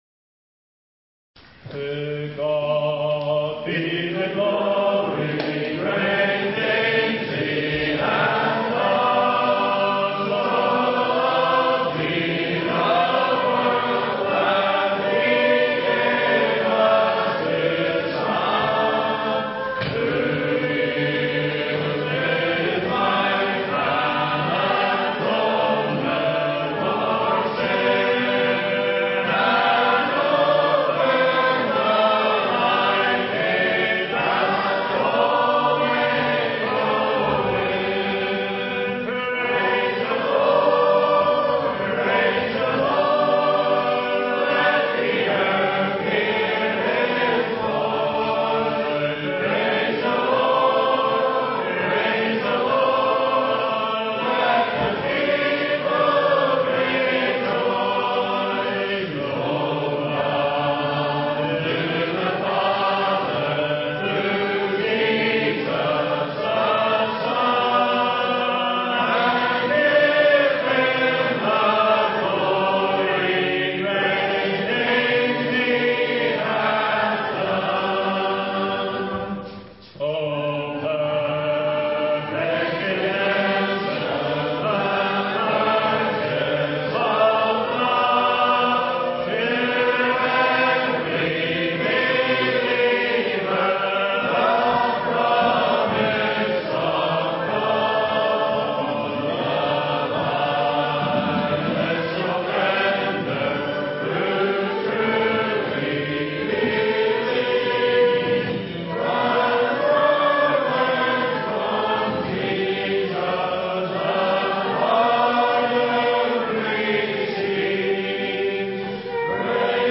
2012 Easter Conference Hymn Singing (Part 1/3)